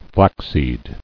[flax·seed]